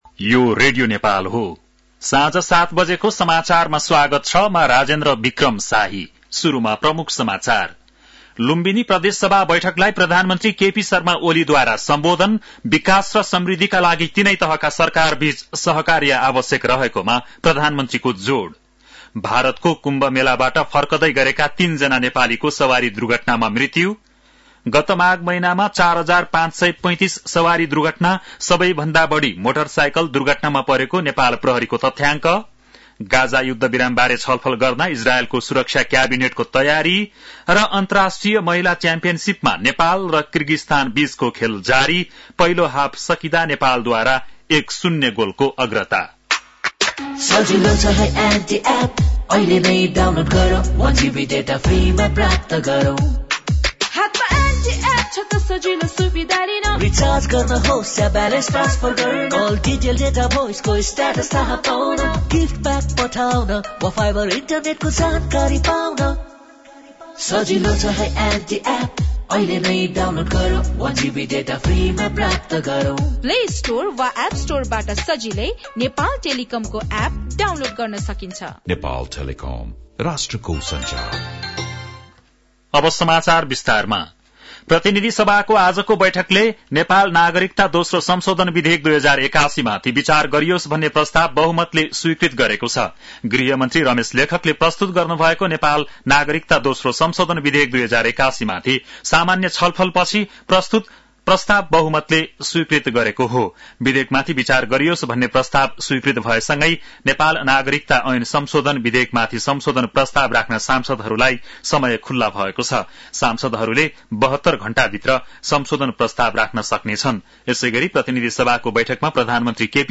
बेलुकी ७ बजेको नेपाली समाचार : ६ फागुन , २०८१
7-pm-nepali-news-11-05.mp3